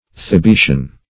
Thibetian \Thi*be"tian\, a. & n.